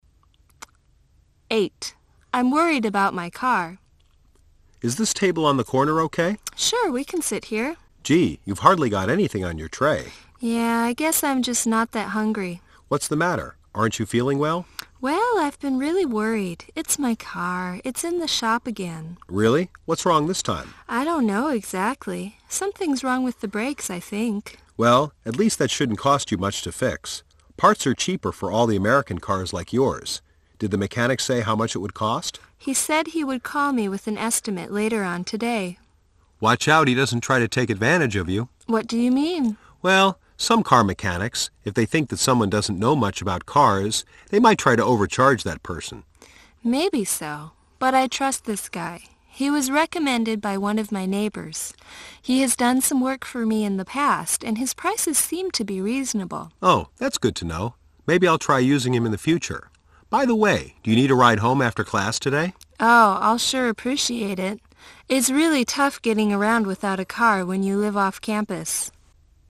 -------1999年8月托福听力试题